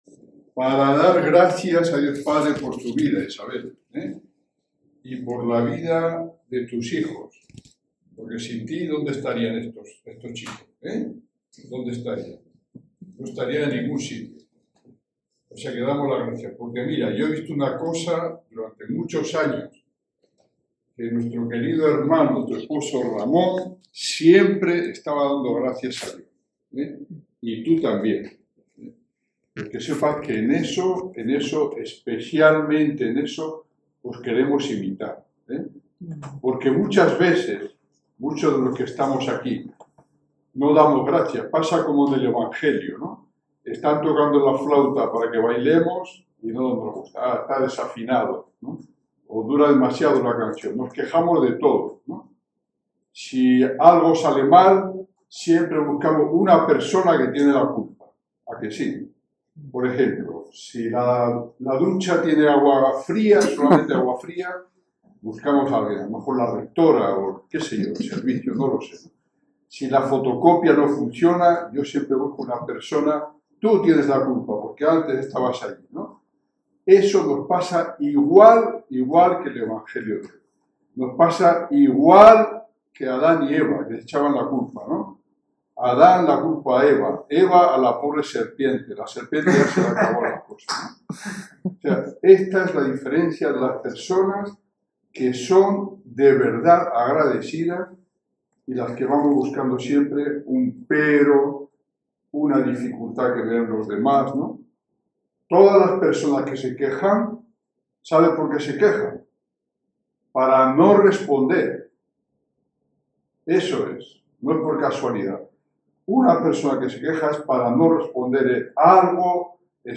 Pronunciada a braccio, la homilía fue tan sencilla como incisiva.